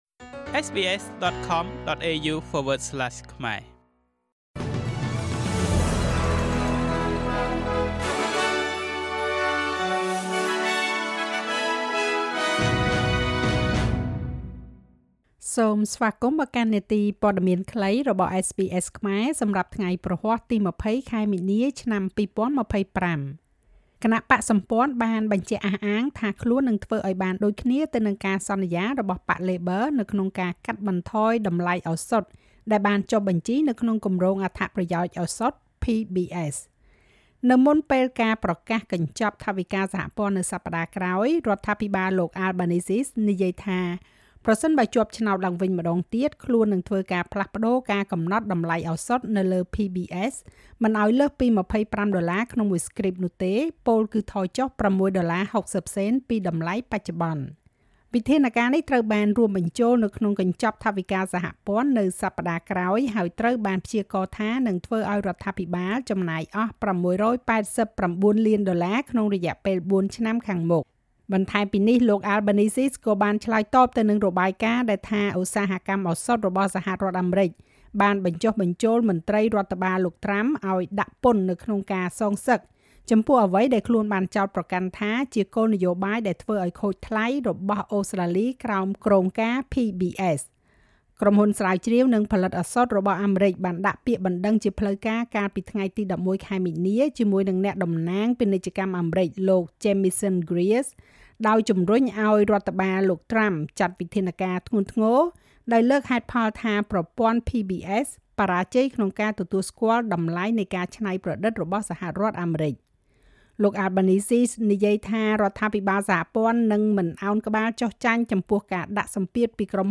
នាទីព័ត៌មានខ្លីរបស់SBSខ្មែរ សម្រាប់ថ្ងៃព្រហស្បតិ៍ ទី២០ ខែមីនា ឆ្នាំ២០២៥